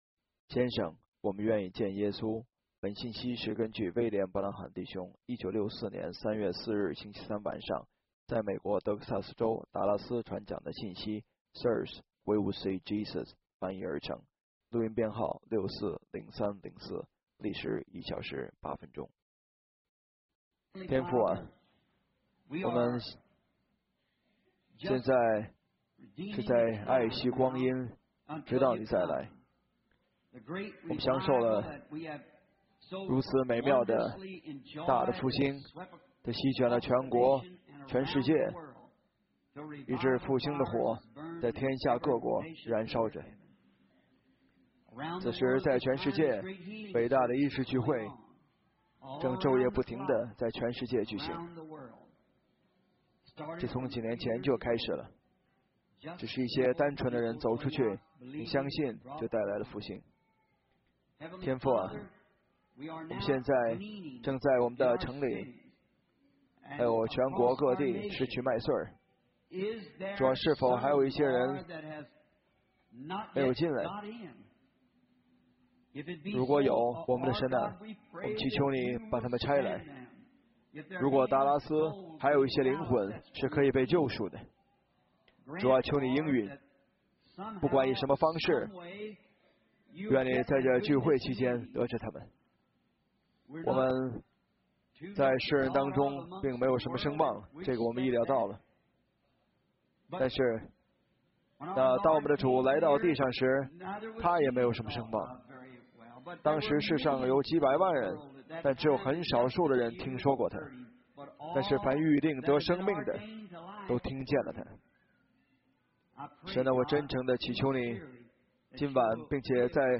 信息录音